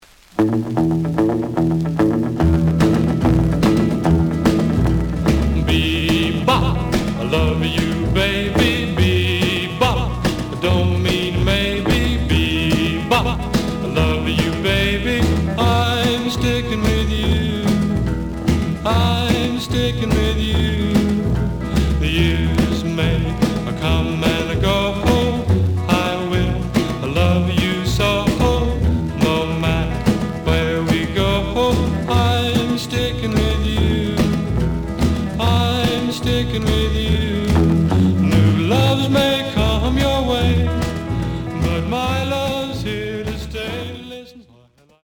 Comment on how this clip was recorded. The audio sample is recorded from the actual item. Slight sound cracking on both sides.